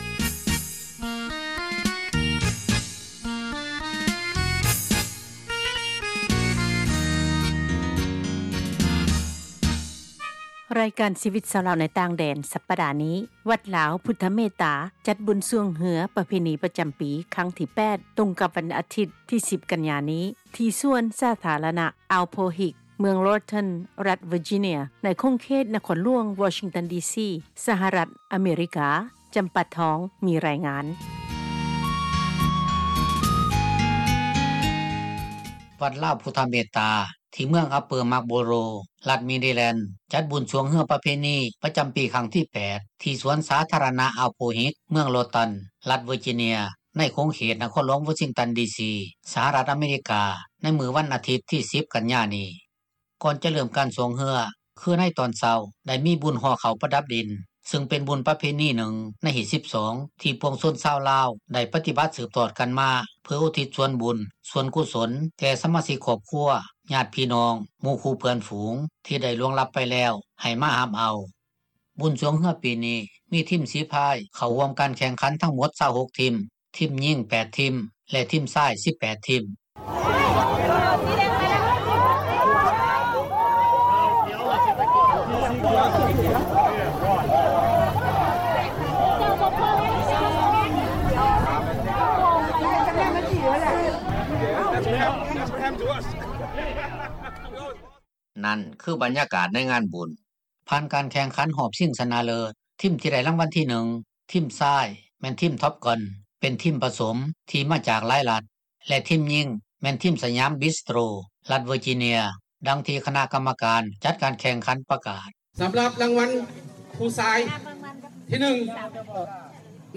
ບຸນຊ່ວງເຮືອປີນີ້ ມີທິມສີພາຍເຂົ້າຮ່ວມການແຂ່ງຂັນທັງໝົດ 26 ທິມ: ທິມຍິງ 8 ທິມ ແລະທິມຊາຍ 18 ທິມ. ..........................ສຽງ.......................... ນັ້ນຄືບັນຍາກາດໃນງານບຸນ.